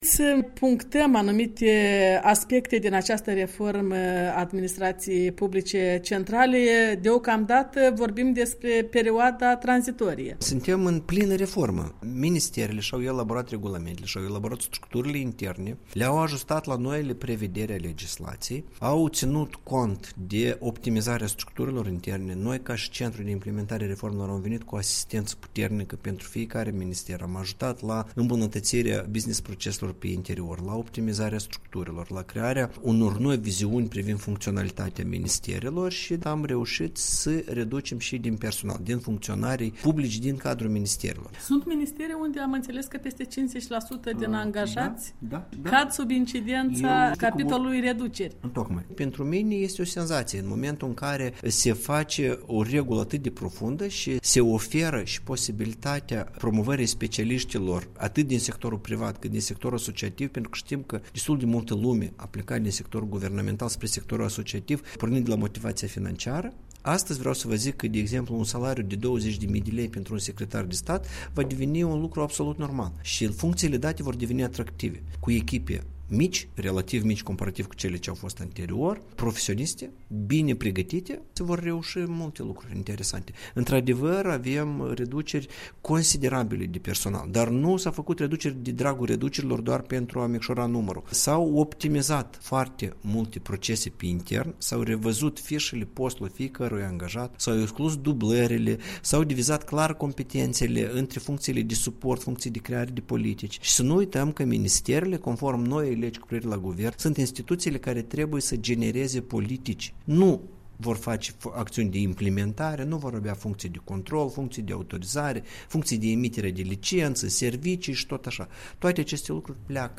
Dialog despre cum se face reforma administrației de la Chișinău
Într-un interviu pentru radio Europa Liberă după ședința de guvern, Iurie Ciocan a precizat însă că bugetul rămâne neschimbat, ceea ce va permite o creștere substanțială a salariilor.